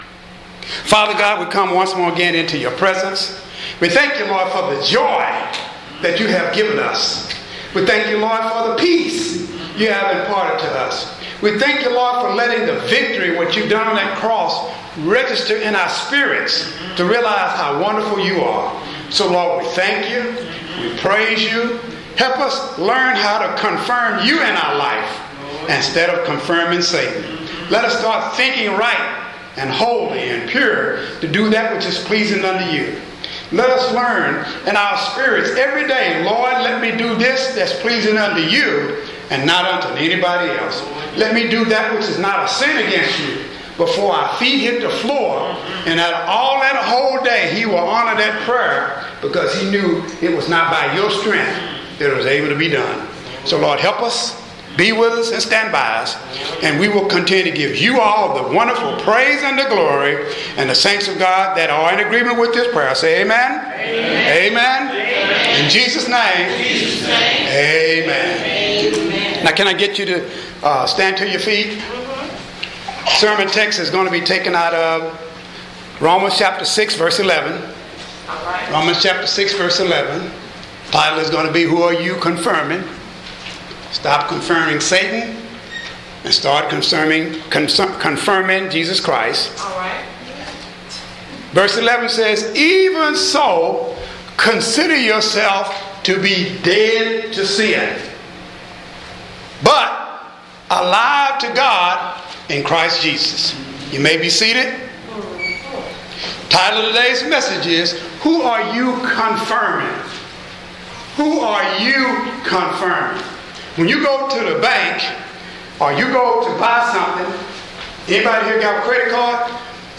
Posted in Audio Sermons Tagged with Audio